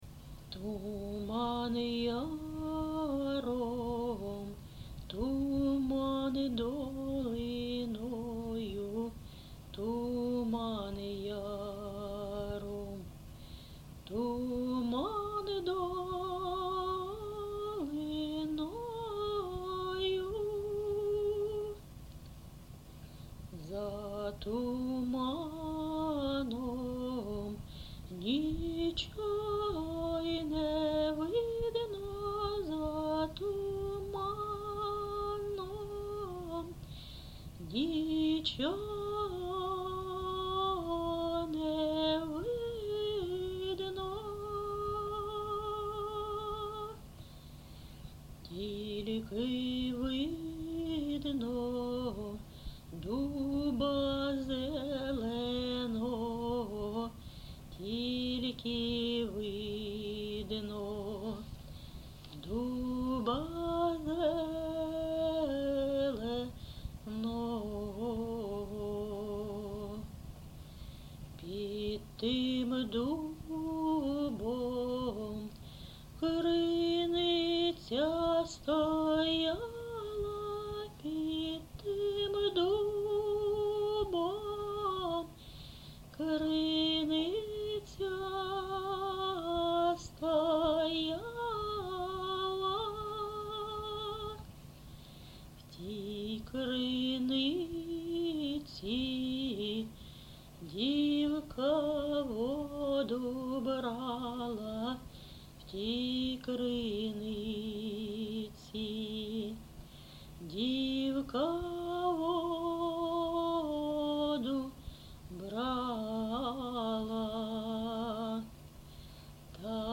ЖанрПісні з особистого та родинного життя
Місце записум. Ровеньки, Ровеньківський район, Луганська обл., Україна, Слобожанщина